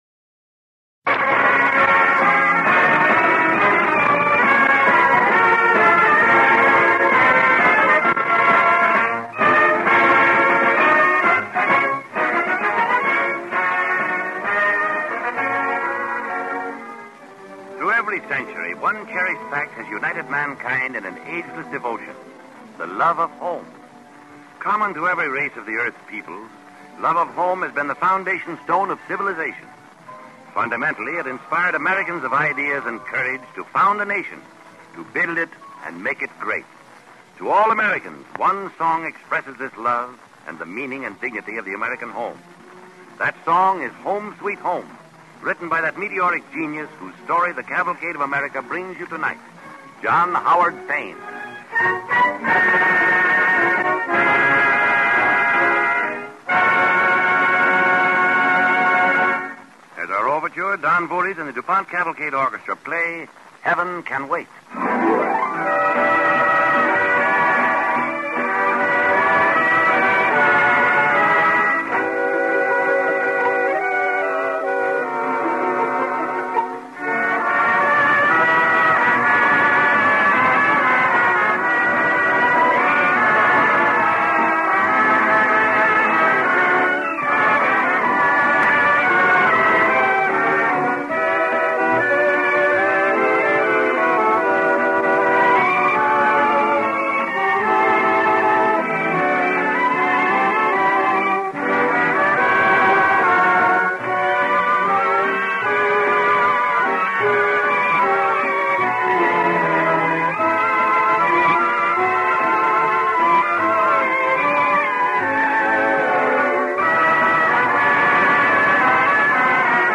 With announcer